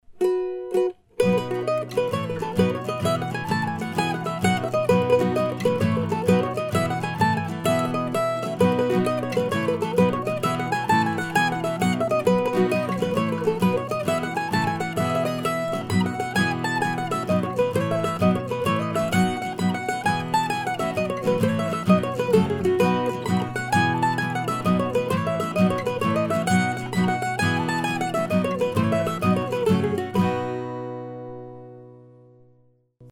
Jigs, pt. 1